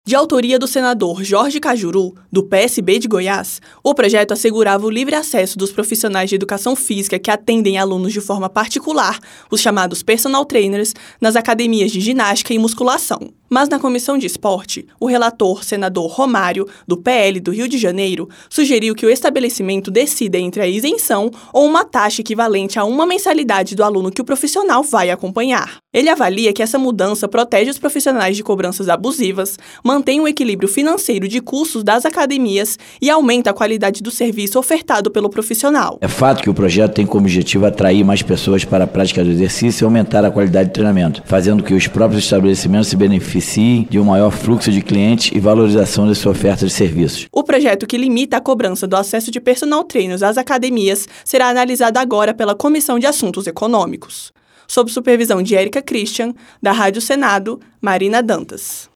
Economia